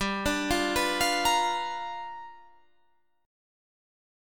G7#9 chord